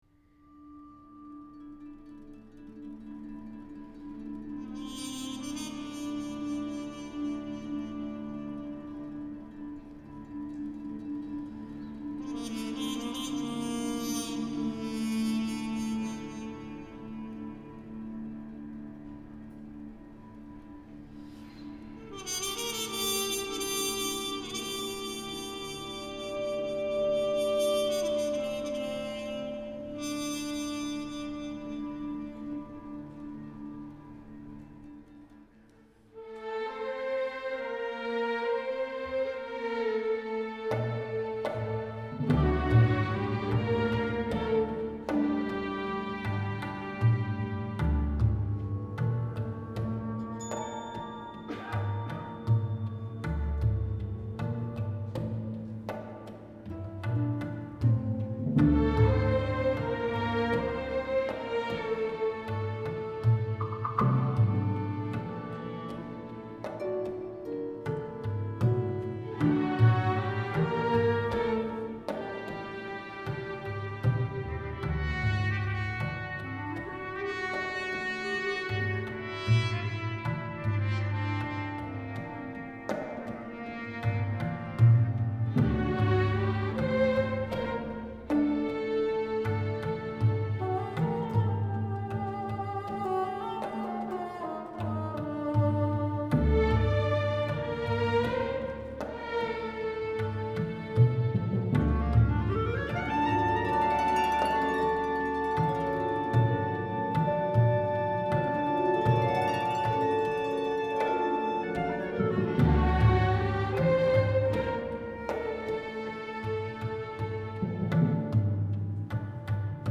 Recorded live